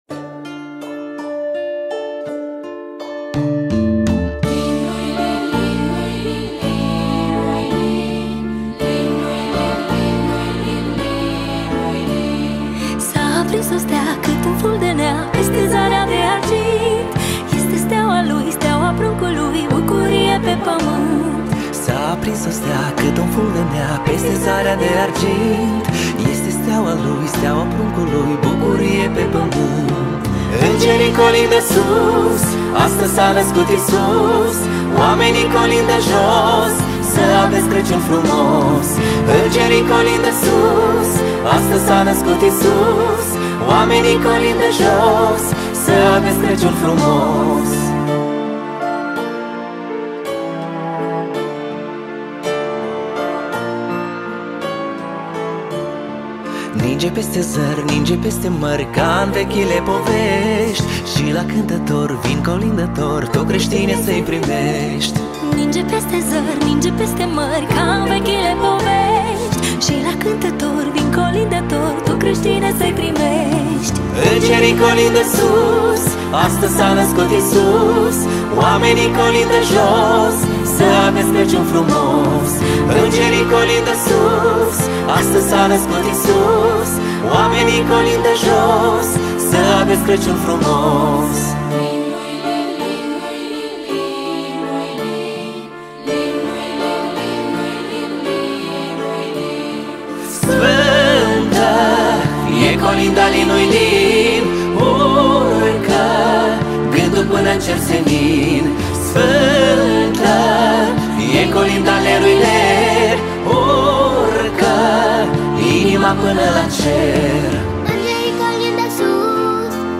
Muzică & vocal